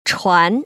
[chuán] 추안